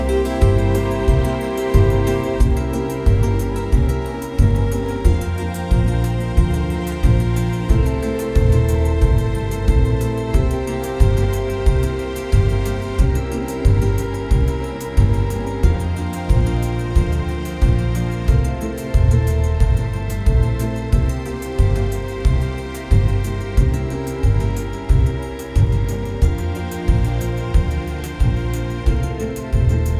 Generate music in stereo, restricted to chord sequences and tempo
"bpm": 90,
"time_sig": "4/4",
"text_chords": "C G:dim7 F:7 A:min",